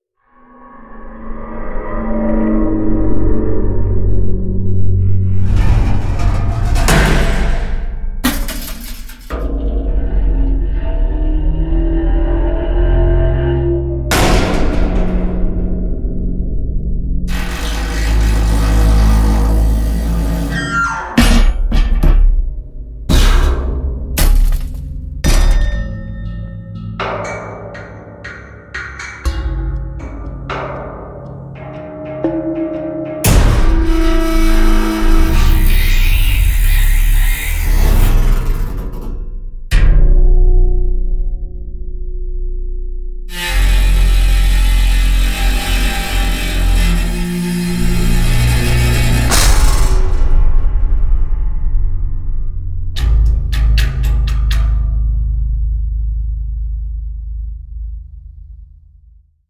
音效素材-实地录音采集金属捶打撞击磕碰无损声音346组
这些声音通过多样化的方式激发产生，包括对金属的踢打、锤击、弓拉，甚至运用反馈循环技术诱使其振动发声，从而捕捉到丰富且真实的金属声效。
所有音效均源自在美国、英国和意大利等地进行的实地录音采集，确保了声音的原始性和高品质。
A-Sound-Effect-Metal-Groans-Slams-Preview.mp3